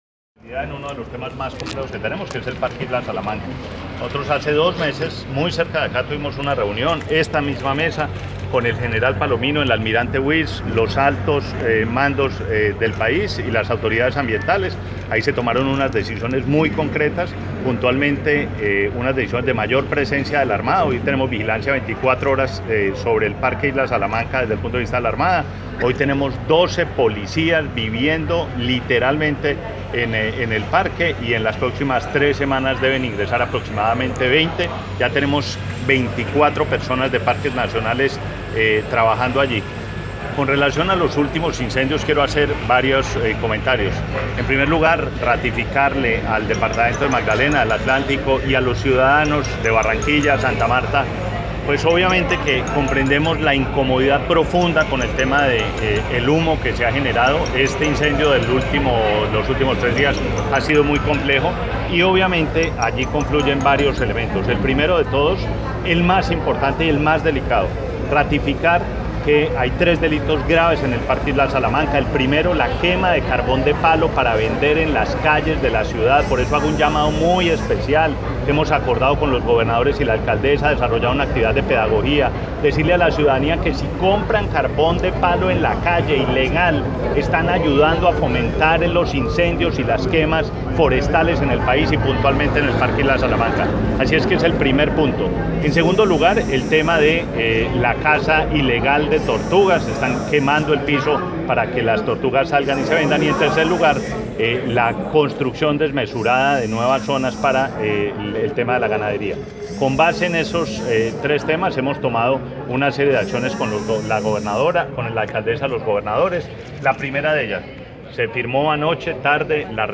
Declaraciones del Ministro de Ambiente y Desarrollo Sostenible, Gabriel Vallejo López